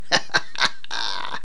laugh_7.mp3